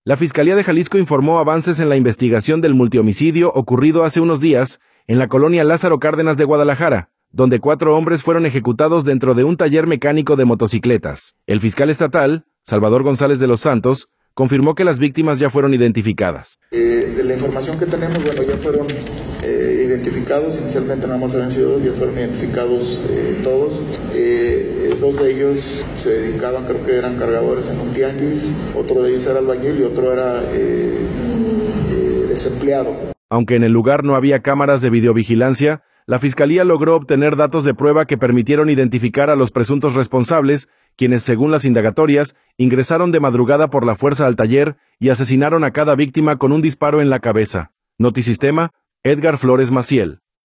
audio La Fiscalía de Jalisco informó avances en la investigación del multihomicidio ocurrido hace unos días en la colonia Lázaro Cárdenas de Guadalajara, donde cuatro hombres fueron ejecutados dentro de un taller mecánico de motocicletas. El fiscal estatal, Salvador González de los Santos, confirmó que las víctimas ya fueron identificadas.